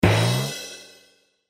bonus_sfx.mp3